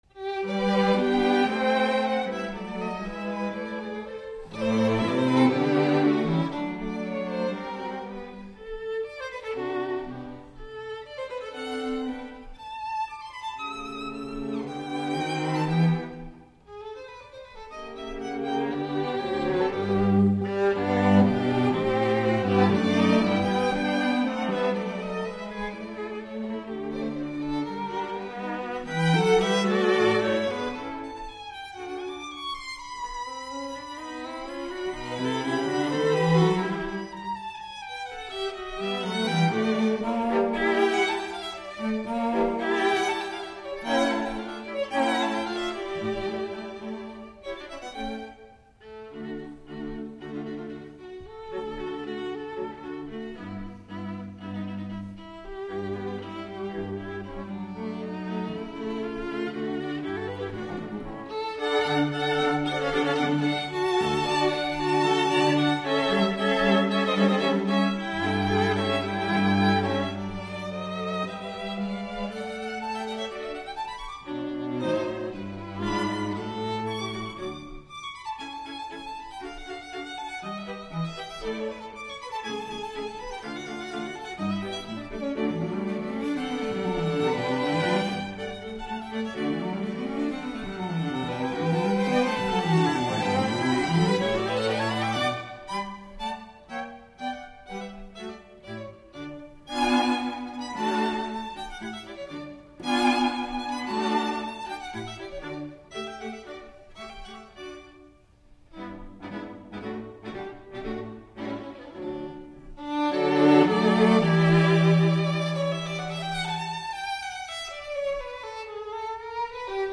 String Quartet in G major
Allegro vivace assai